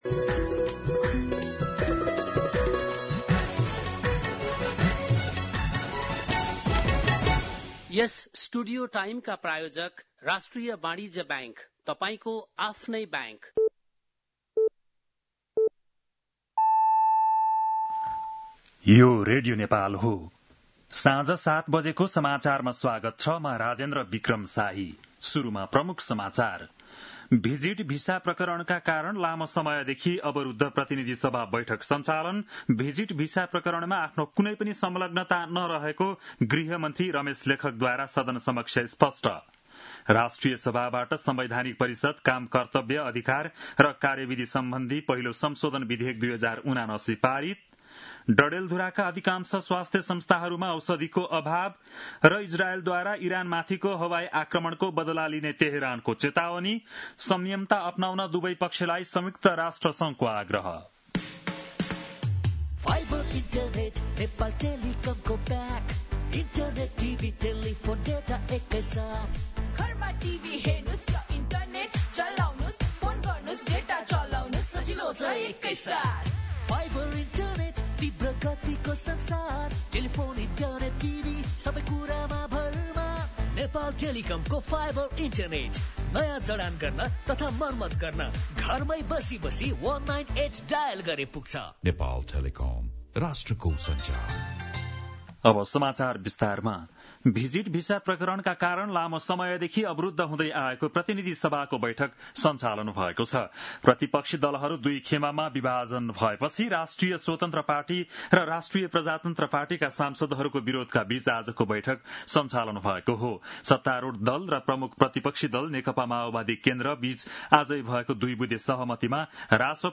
बेलुकी ७ बजेको नेपाली समाचार : ३० जेठ , २०८२